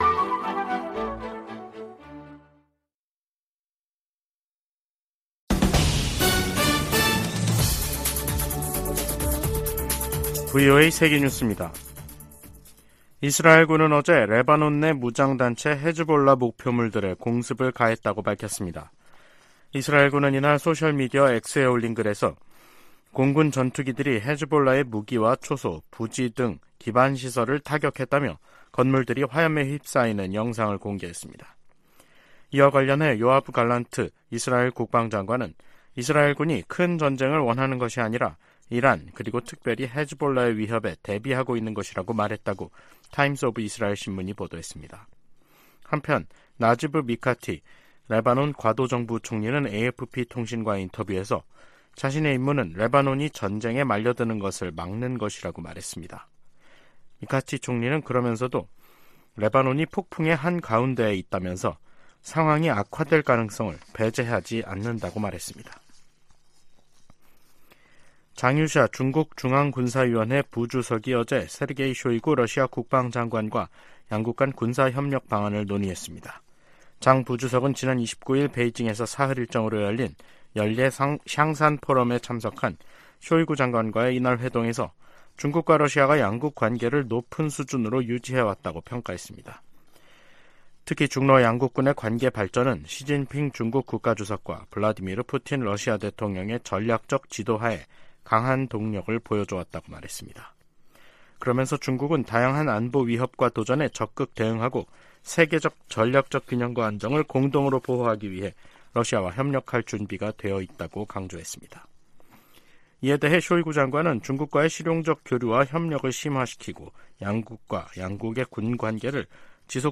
VOA 한국어 간판 뉴스 프로그램 '뉴스 투데이', 2023년 10월 31일 2부 방송입니다. 미 국무부 대북특별대표가 중국 한반도사무 특별대표와 화상회담하고 북러 무기거래가 비확산 체제를 약화시킨다고 지적했습니다. 하마스가 북한제 무기를 사용했다는 정황이 나온 가운데 미 하원 외교위원장은 중국·이란에 책임을 물어야 한다고 주장했습니다. 북한의 핵 위협에 대한 한국 보호에 미국 핵무기가 사용될 것을 확실히 하는 정책 변화가 필요하다는 보고서가 나왔습니다.